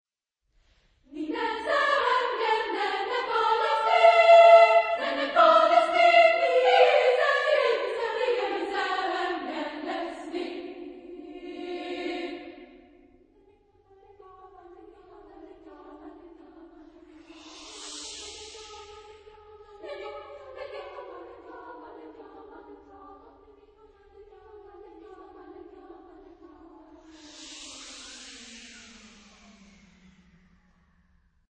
Epoque : 20ème s.
Genre-Style-Forme : Suite ; contemporain ; Profane